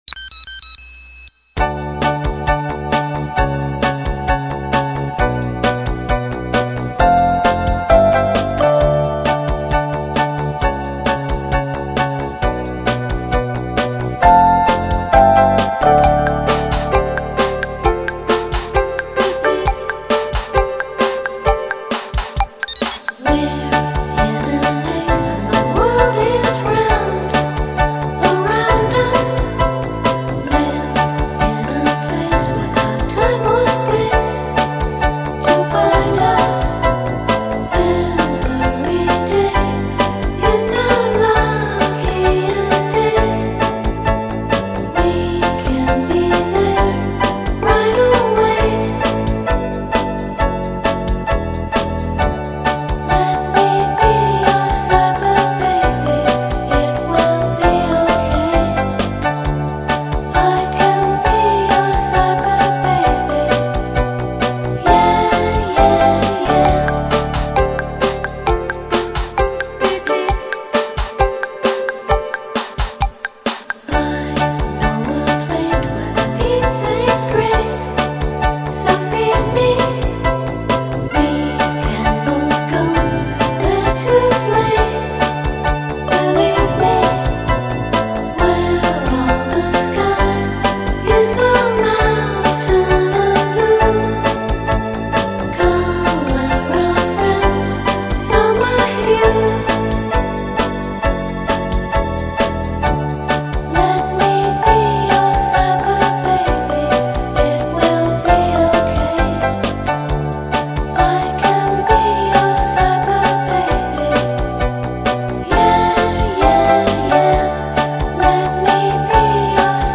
Música
es un electro-pop repleto de candor